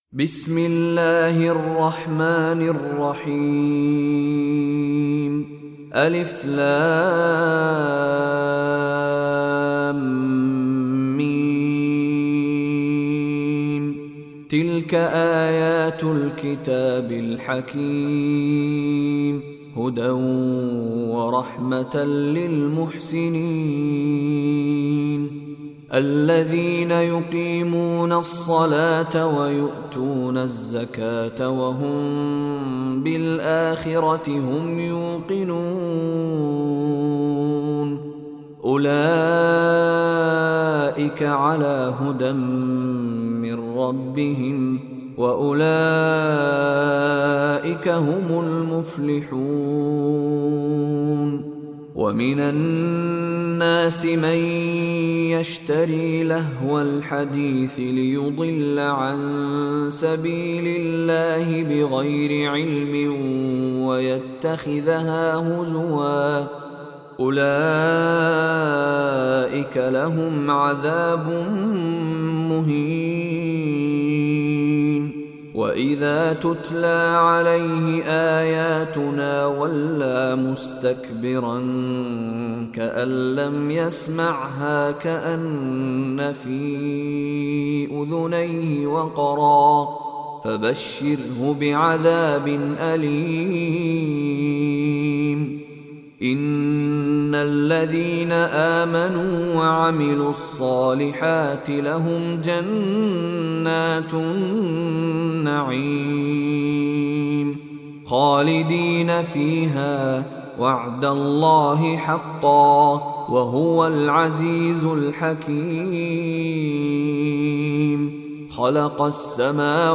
Surah Luqman Recitation by Mishary Rashid
Surah Luqman is 31st chapter of Holy Quran. Listen beautiful recitation of Surah Luqman in the voice of Sheikh Mishary Rashid Alafasy.